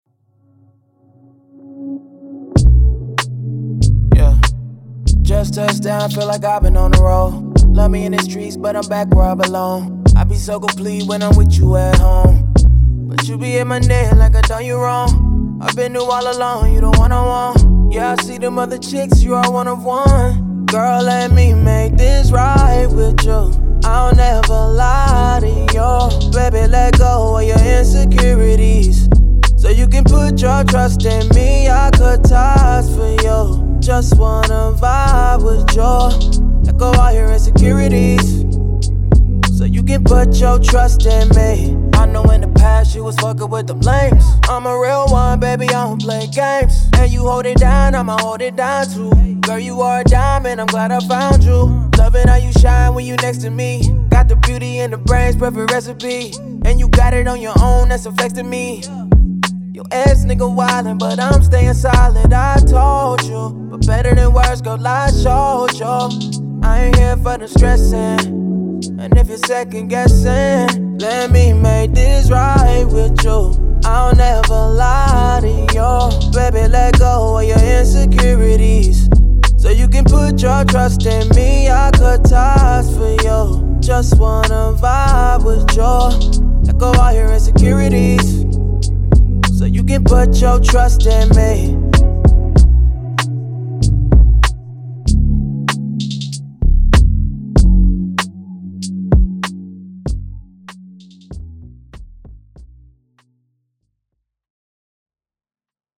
R&B
F# Minor